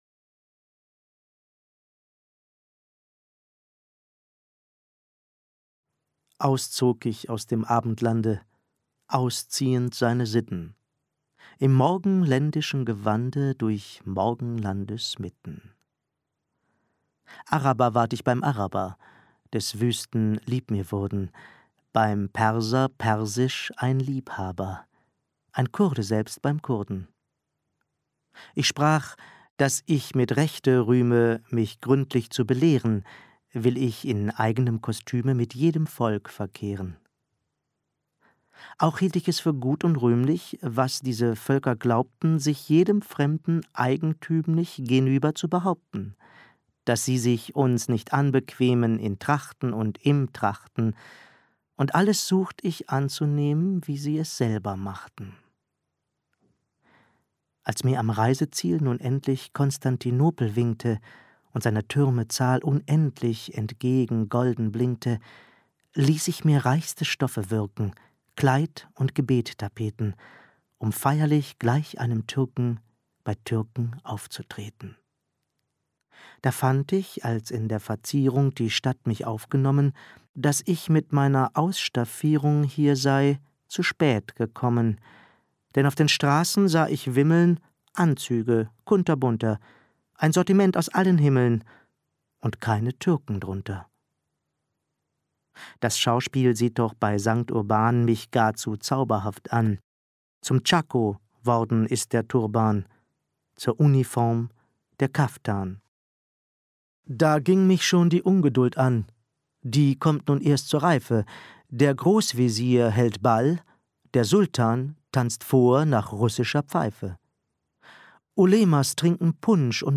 Rezitation : Gustav Peter Wöhler